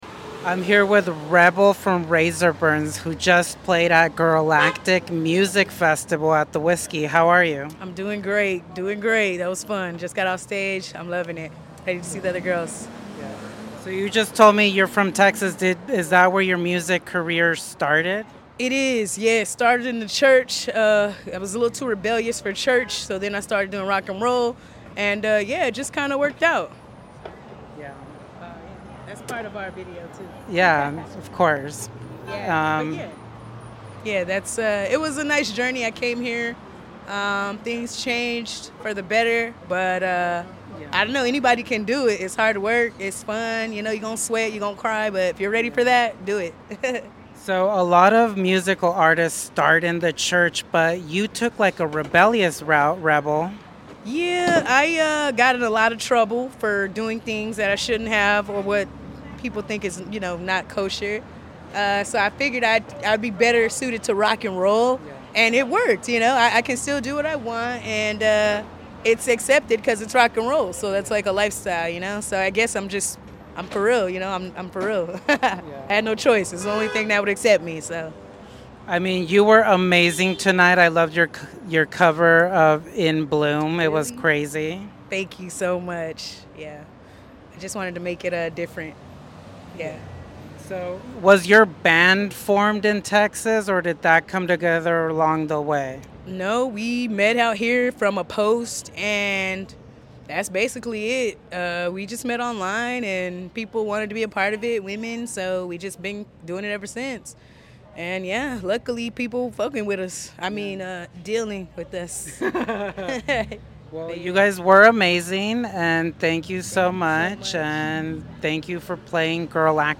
Chat
at Girlactic Music Festival